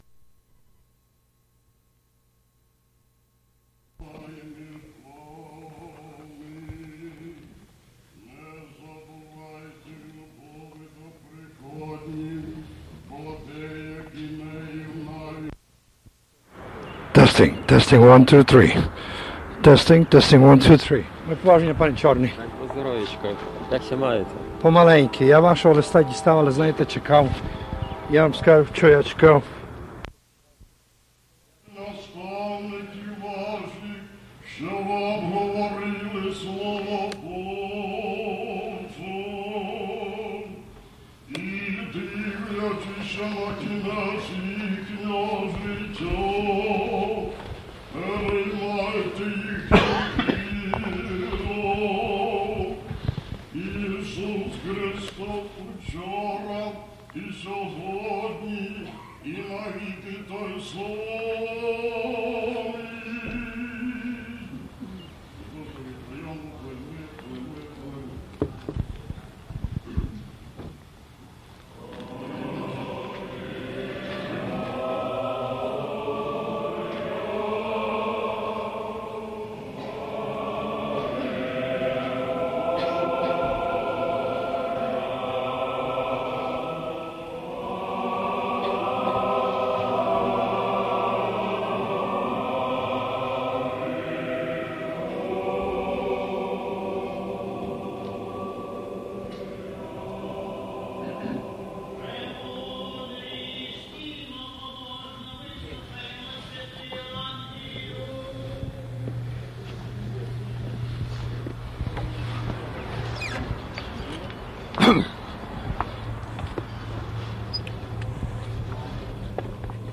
Funeral liturgy for Hryhory Kytasty
Microphone check
Gospel reading
Sermon by Metropolitan Mstyslav